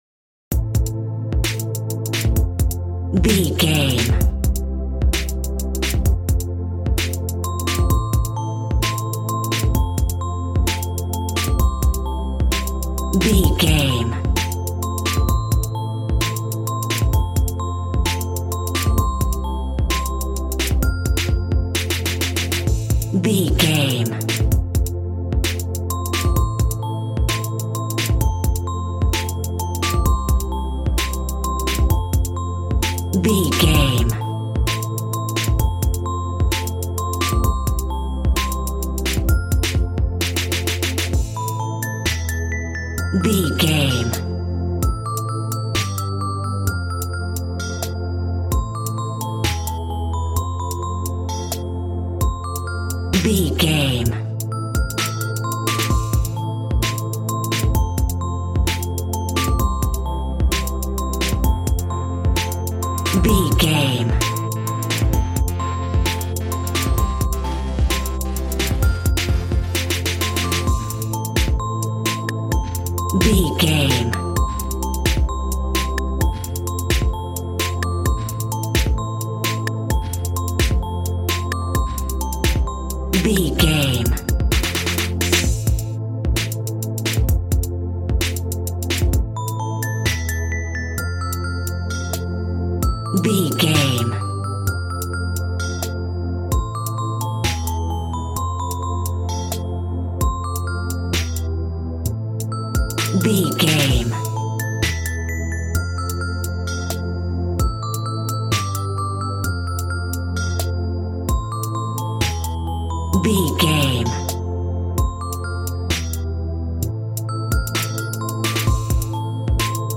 Ionian/Major
calm
smooth
synthesiser
piano